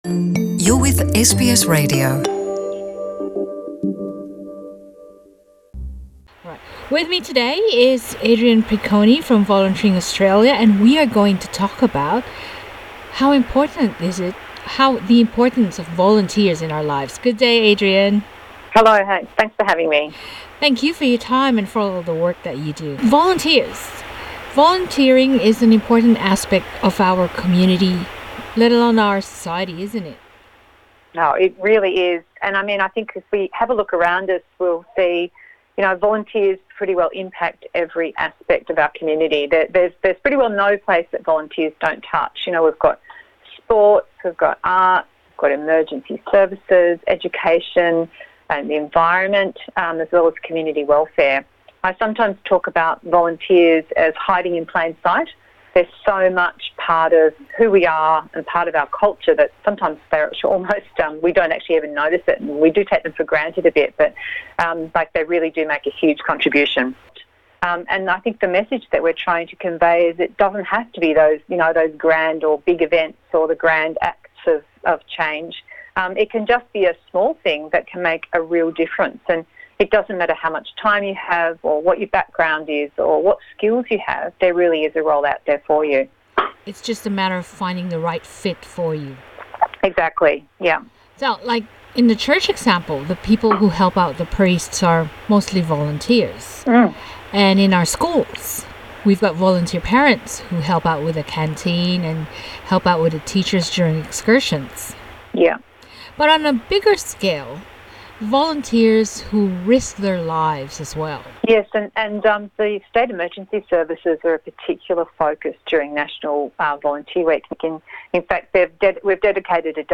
Narito ang aming panayam